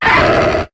Cri de Lucario dans Pokémon HOME.